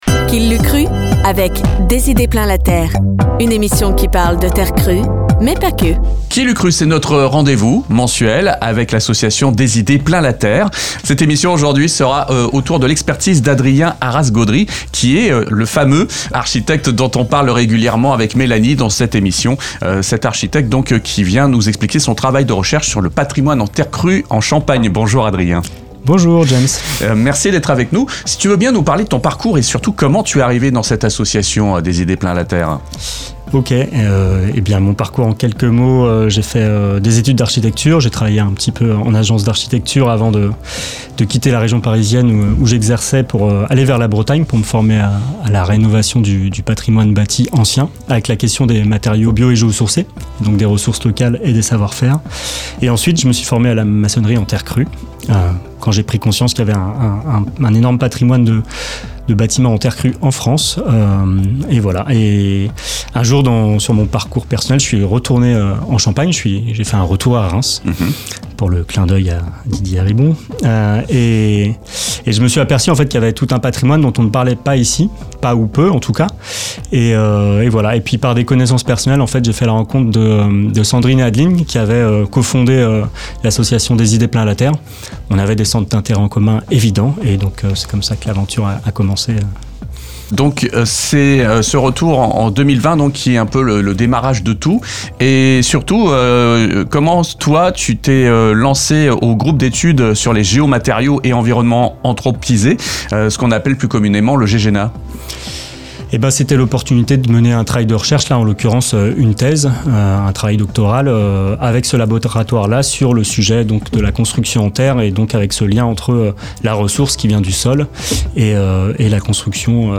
Chronique de novembre (14:43)